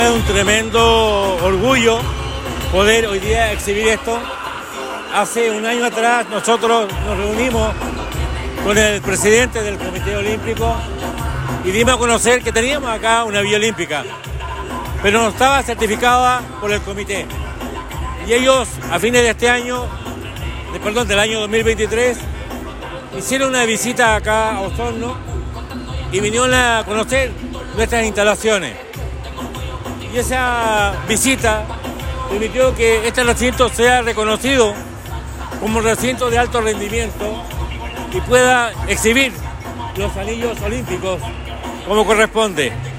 Ante esto el Alcalde Emeterio Carrillo, señaló que recibir los anillos olímpicos para la comuna es relevante, pues de esta forma de certifican las instalaciones locales, reconociendolos como centros de alto rendimiento.